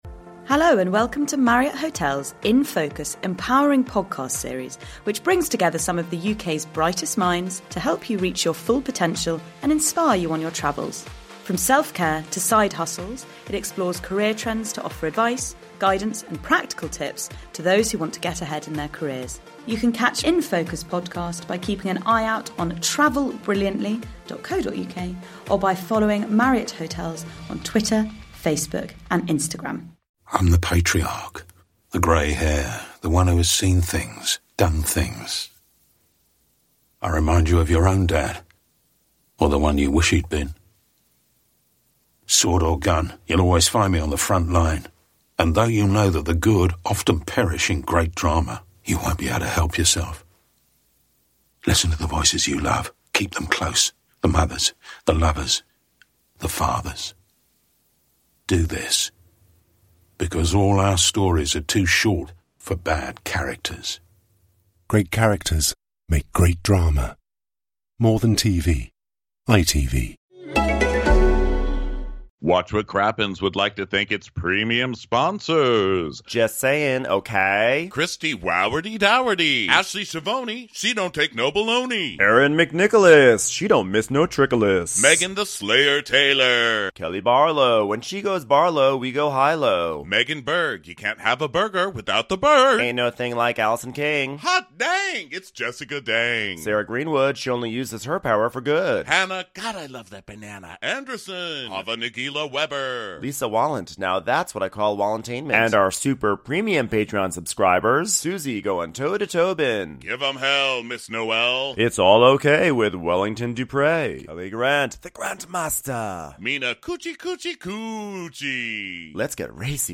Brace yourself for a trip down memory lane (via dented trolley) as we recap a classic episode from the first season of the Real Housewives of Dallas. And of course, there's no better place to do it than at the historic Texas Theatre in the heart of Dallas itself! This was our biggest audience to date, and if you missed us, don't worry: we're coming back to Texas next month for SXSW!!!